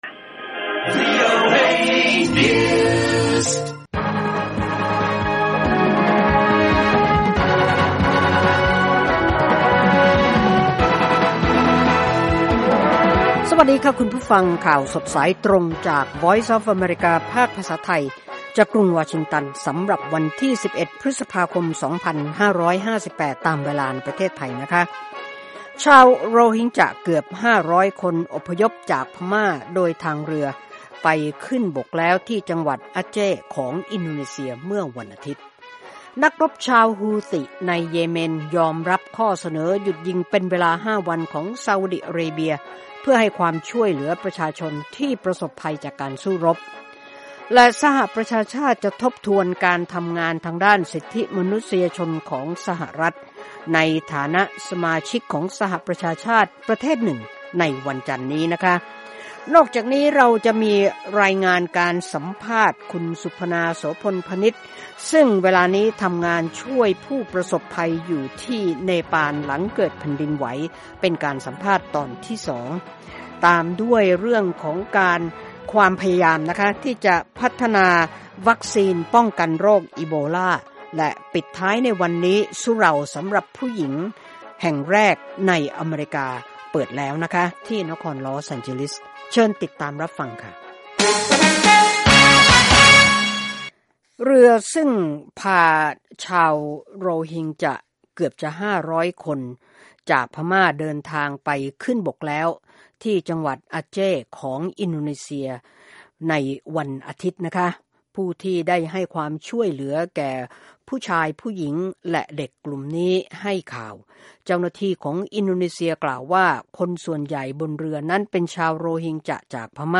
ข่าวสดสายตรงจากวีโอเอ ภาคภาษาไทย 6:30 – 7:00 น. วันจันทร์ ที่ 11 พฤษภาคม 2558